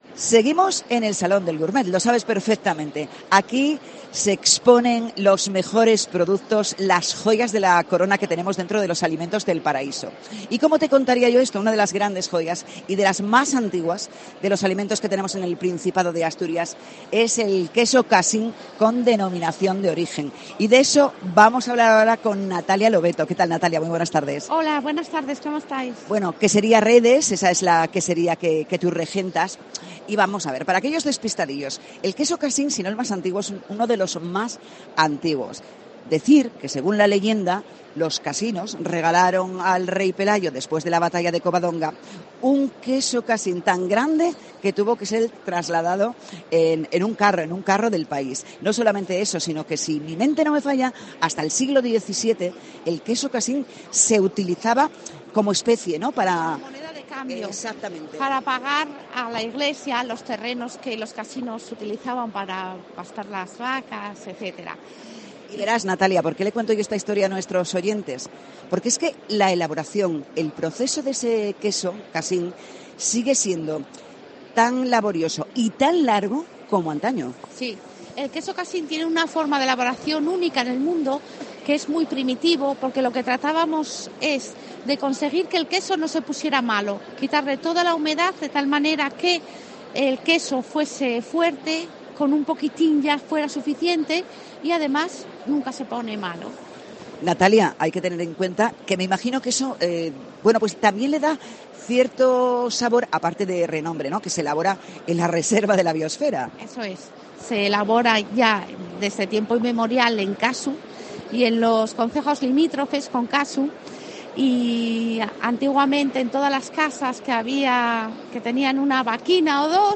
Salón Gourmets 2023: entrevista